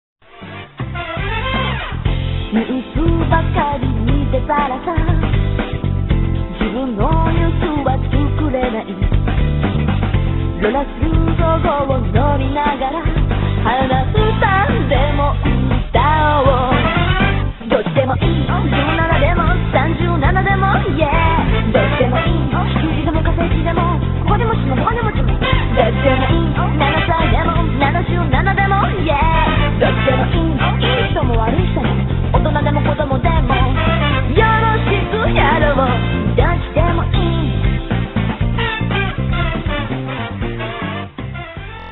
a cool rock&roll song